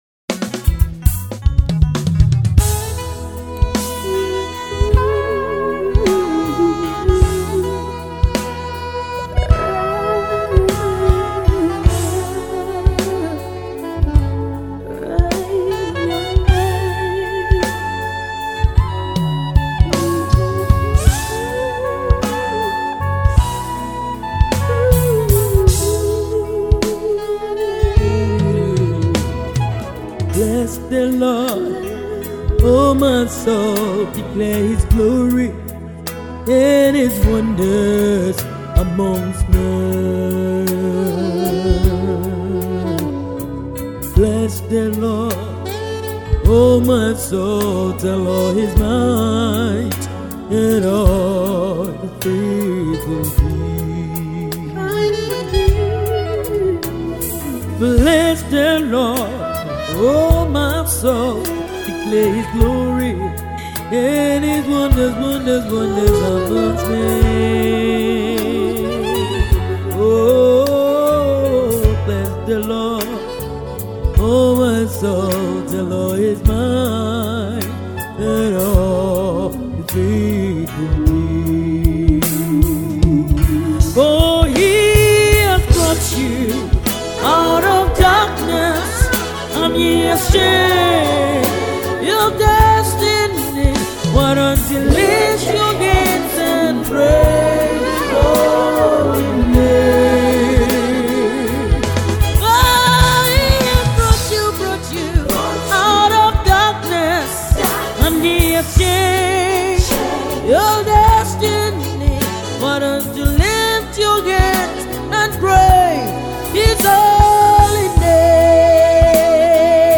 GospelMusic
Gospel